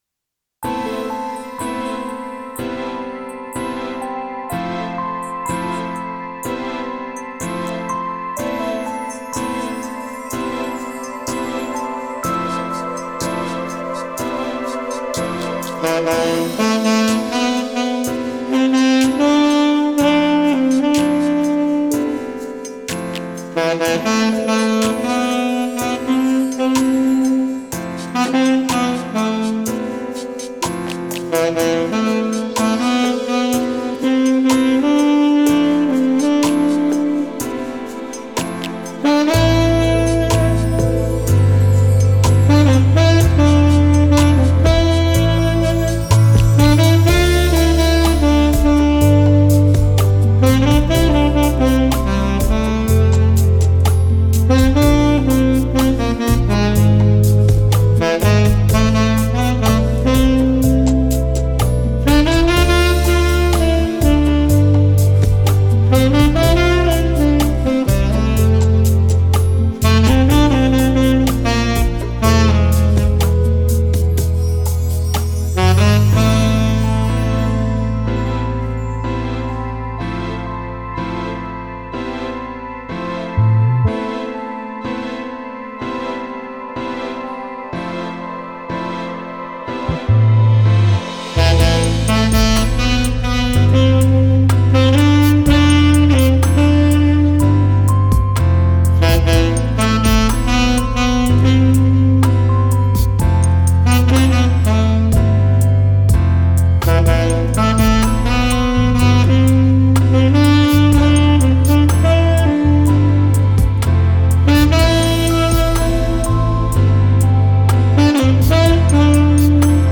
Жанр: Downtempo, Chillout, Lounge